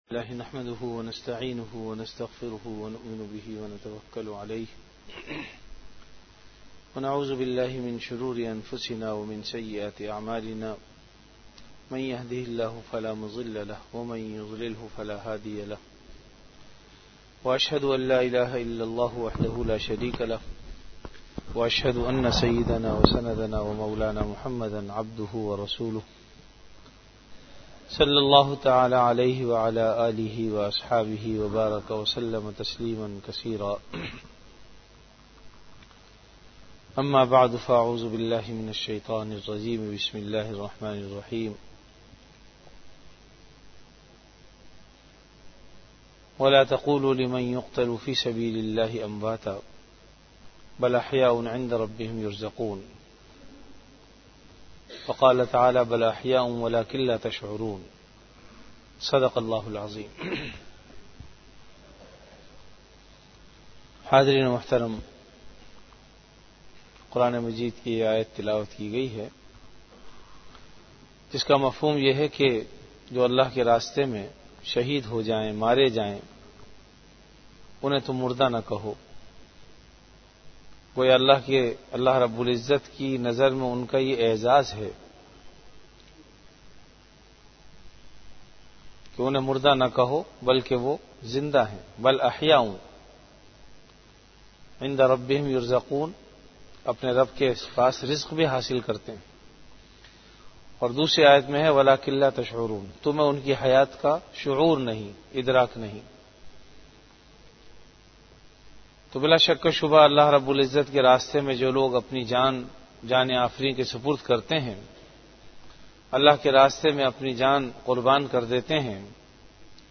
Bayanat · Jamia Masjid Bait-ul-Mukkaram, Karachi
Venue Jamia Masjid Bait-ul-Mukkaram, Karachi Event / Time After Isha Prayer